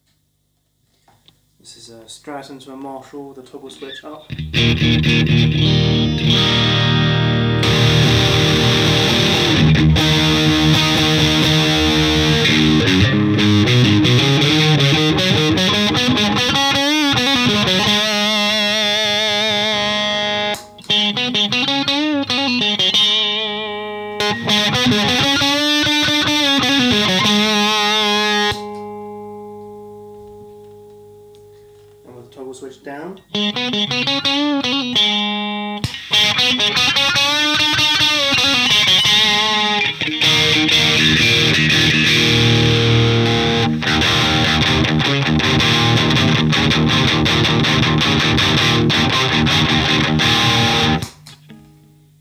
This pedal has an AC128 germanium transistor.
Homemade 60s Strat with Fender Fat 50s pickups
All played through a Marshall JCM900 50W High Gain Master Volume, Marshall 1936 2x12" cab. Recorded with a cheap microphone at low volume.
TB Strat Marshall.wav